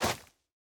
Minecraft Version Minecraft Version 1.21.5 Latest Release | Latest Snapshot 1.21.5 / assets / minecraft / sounds / block / netherwart / step4.ogg Compare With Compare With Latest Release | Latest Snapshot
step4.ogg